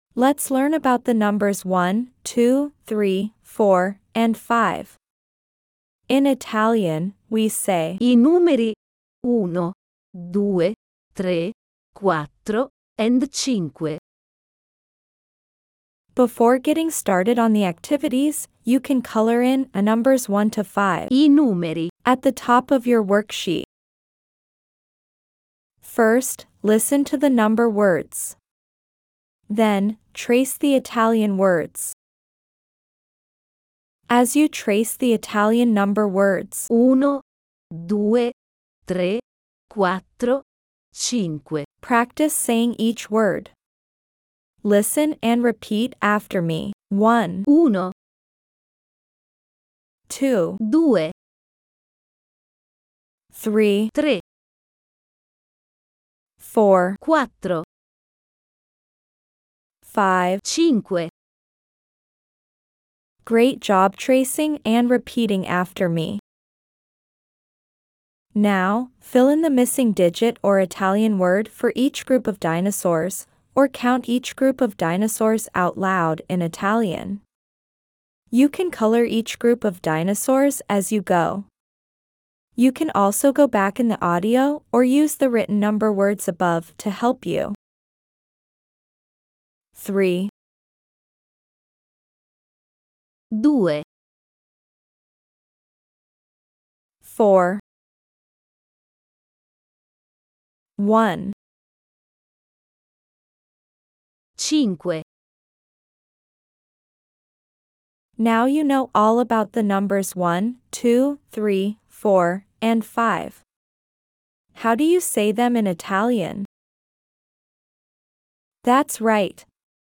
If you haven’t received your first box yet, you’ll find a free introduction to number words and an accompanying audio file for pronunciation at the end of this post.